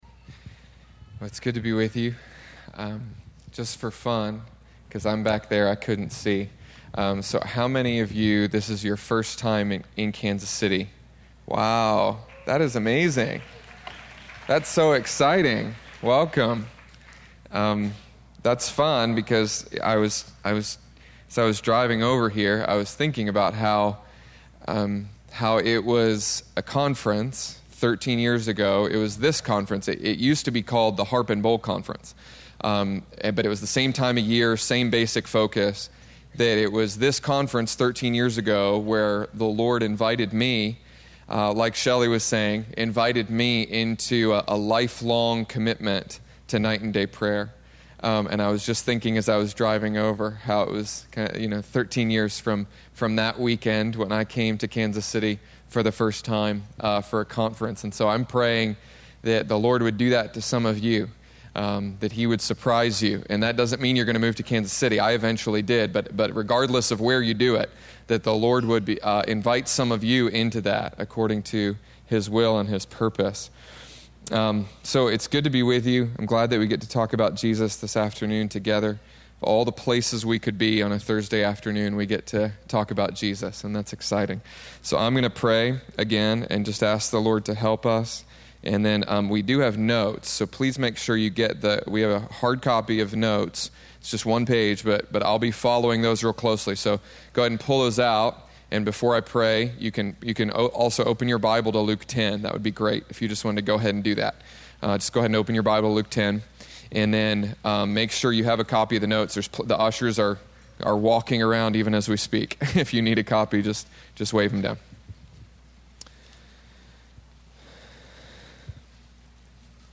Spoken on October 10th, 2013 at the Prayer and Prophetic Conference hosted by IHOPKC.